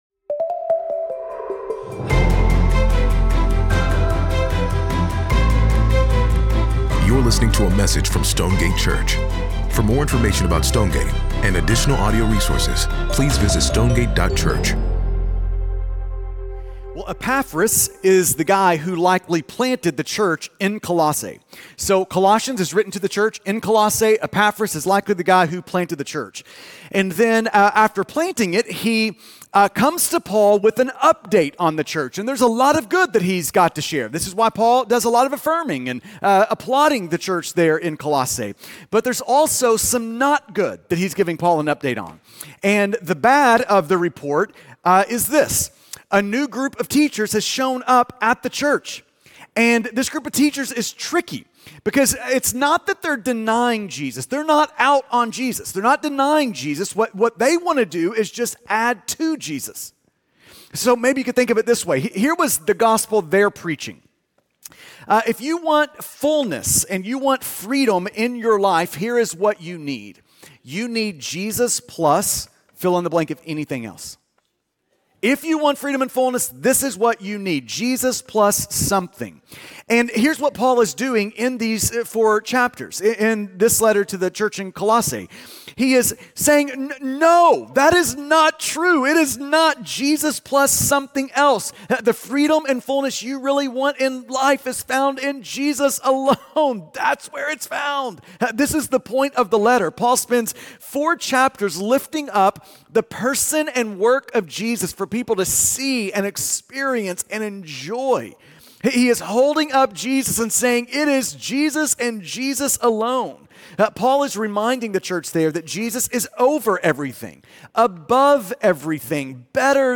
sermon pod 9.7.mp3